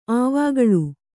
♪ āvāgaḷu